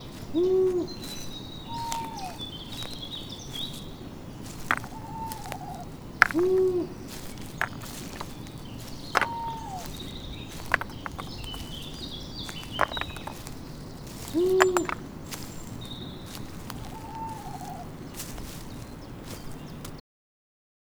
Sons-forêt-cailloux.mp3